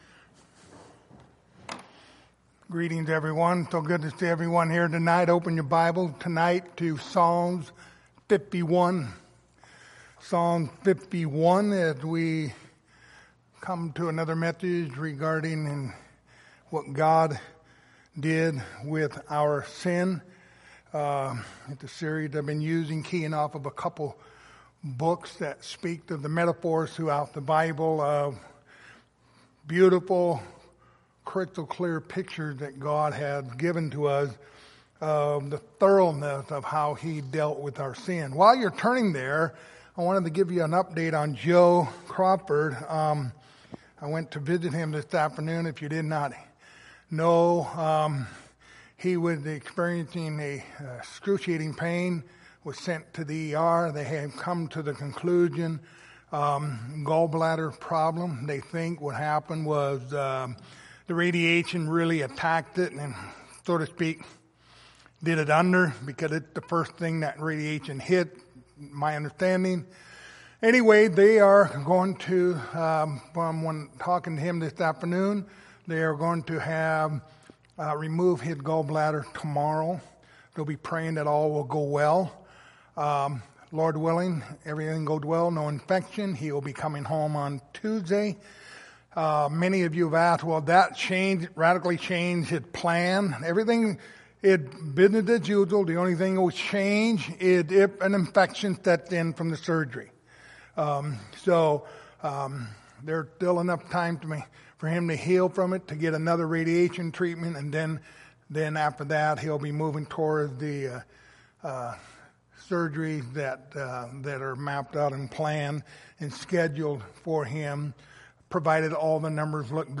Passage: Psalms 51:1-19 Service Type: Lord's Supper Topics